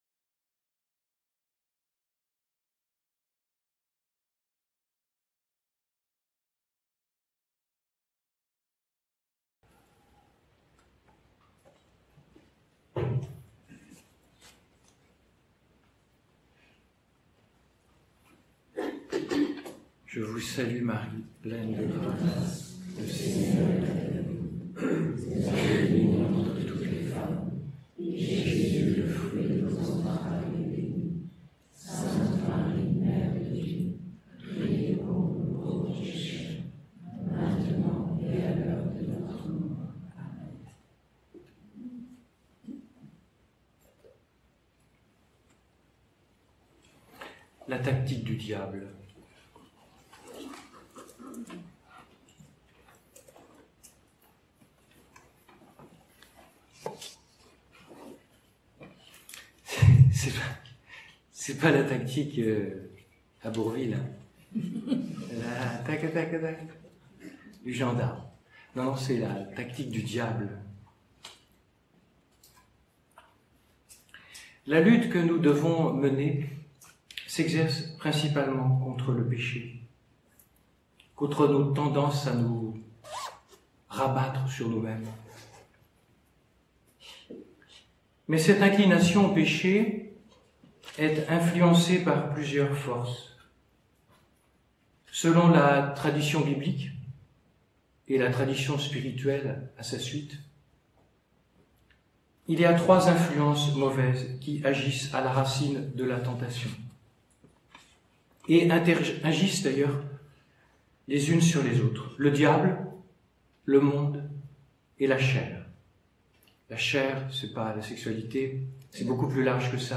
Comme le son des vidéos n’était ni très fort, ni très « propre », on vous propose de télécharger un fichier son amélioré pour chacune d’entre elles, afin de faciliter votre écoute.